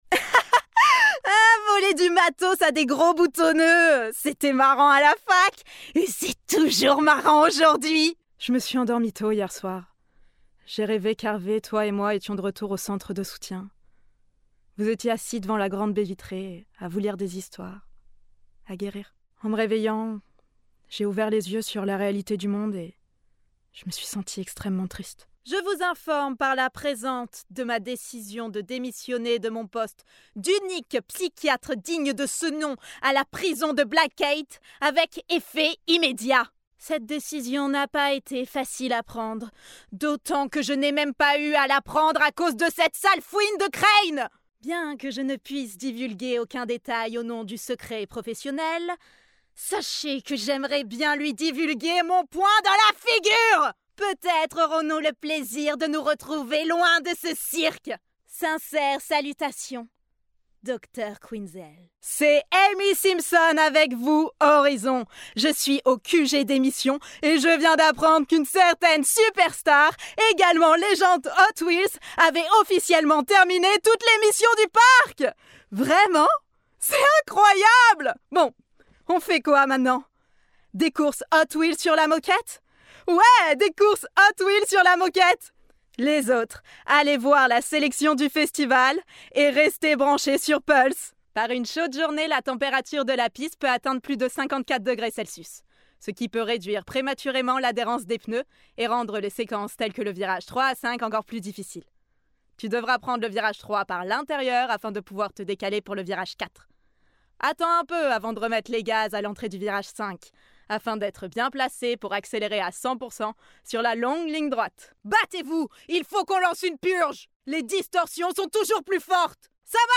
Bande demo voix
5 - 37 ans - Mezzo-soprano